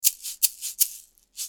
Free MP3 shakers sounds 2